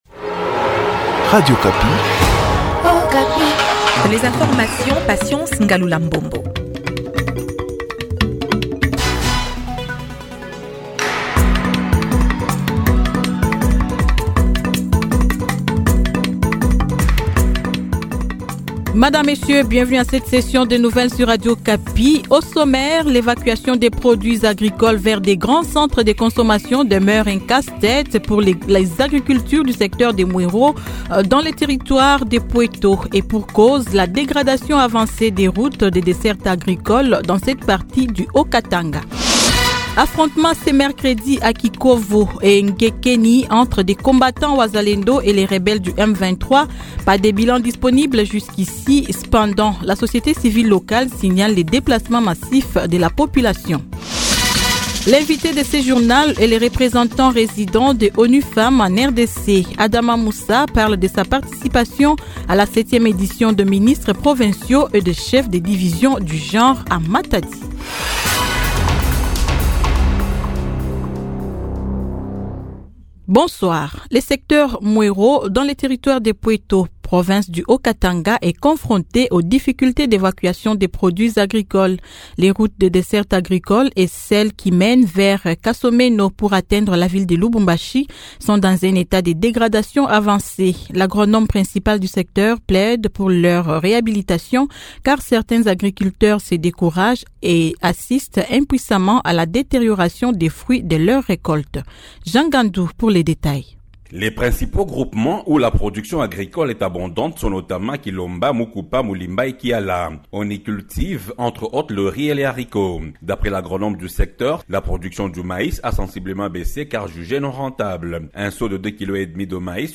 Journal Soir 18H00